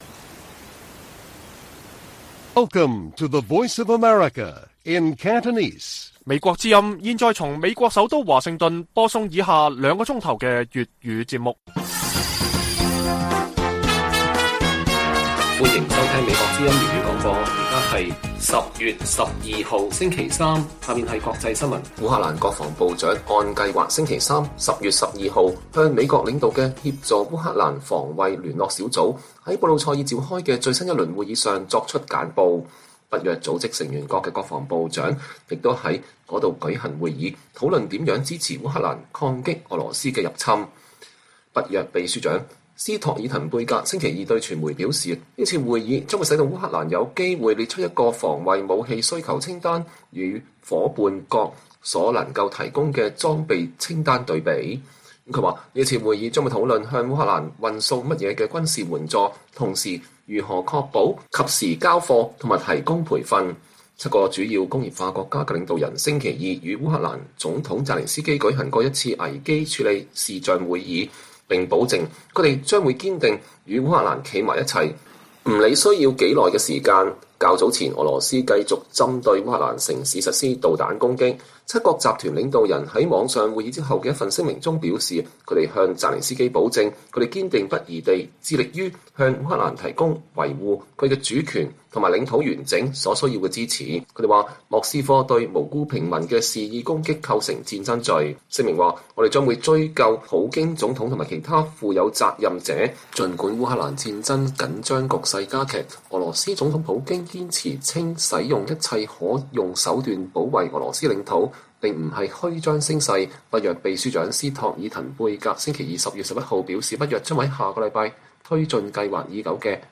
粵語新聞 晚上9-10點: 美國領導的聯盟開會決定下一步如何幫助烏克蘭抗擊俄國侵略